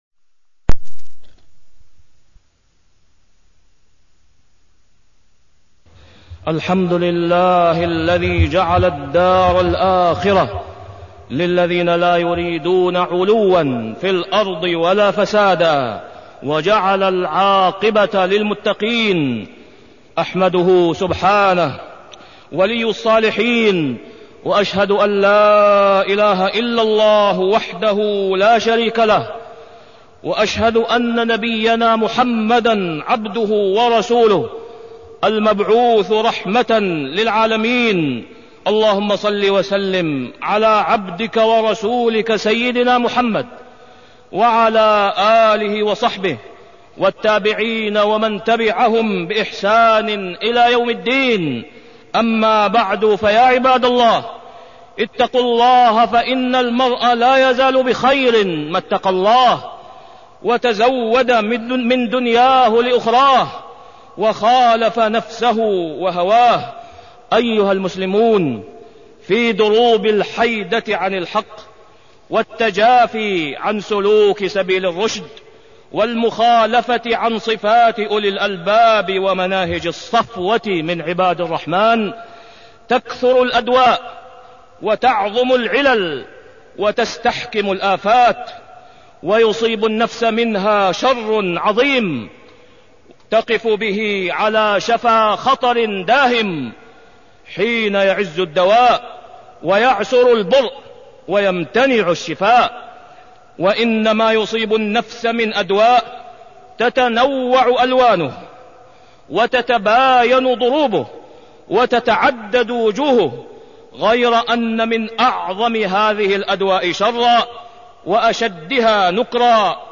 تاريخ النشر ١٦ جمادى الأولى ١٤٢٣ هـ المكان: المسجد الحرام الشيخ: فضيلة الشيخ د. أسامة بن عبدالله خياط فضيلة الشيخ د. أسامة بن عبدالله خياط الكبر